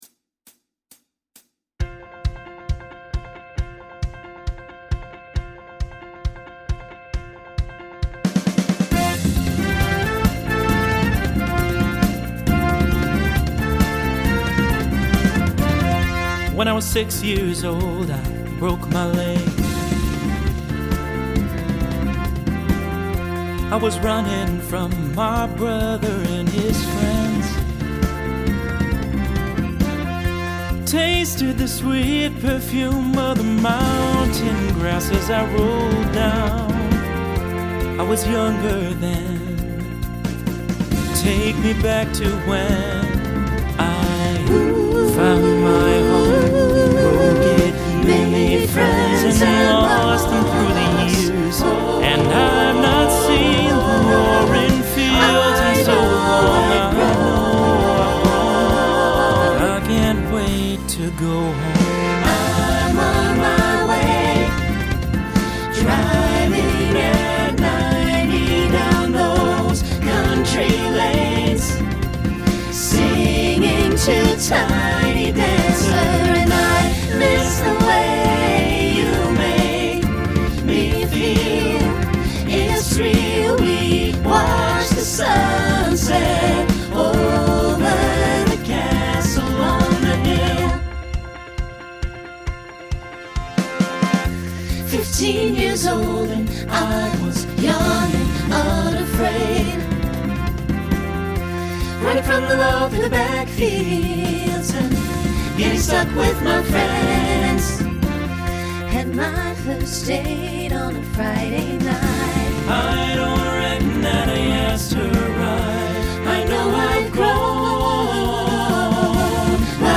Genre Folk , Rock
Voicing SATB